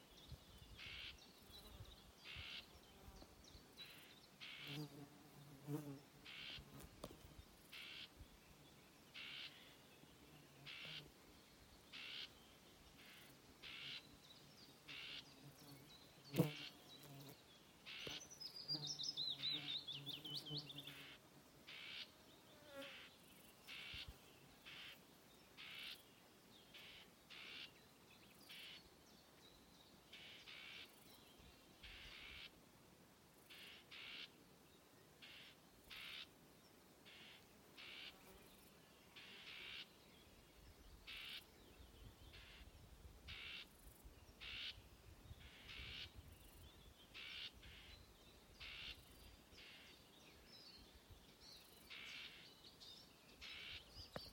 Birds -> Warblers ->
Whitethroat, Curruca communis
StatusAgitated behaviour or anxiety calls from adults